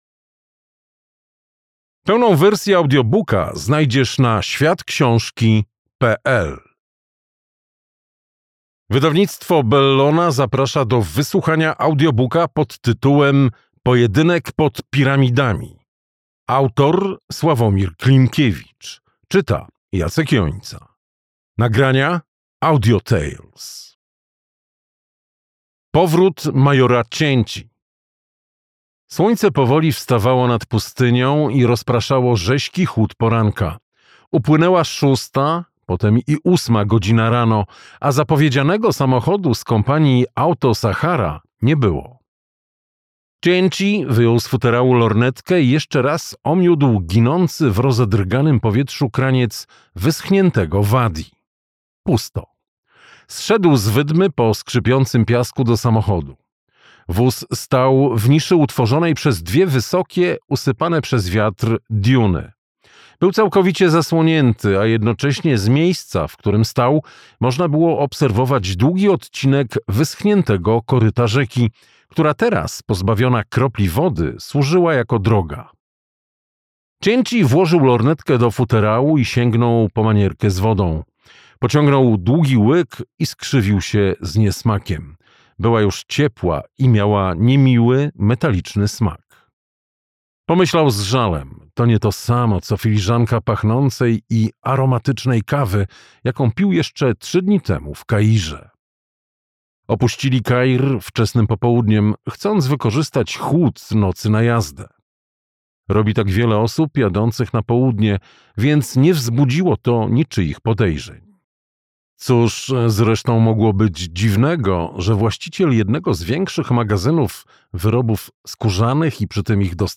Pojedynek pod piramidami - Klimkiewicz Sławomir - audiobook